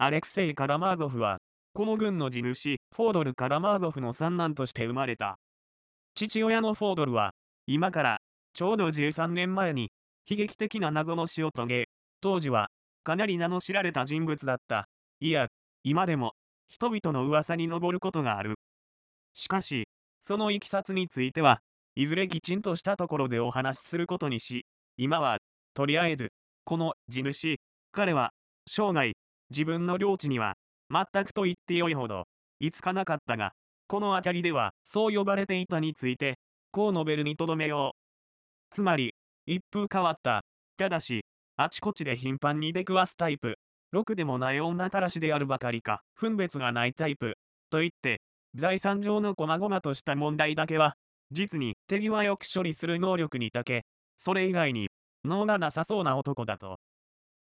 電子書籍や、音声朗読機能を使ってみるのも、良さそうなので、試しに作ってみました。
女性の声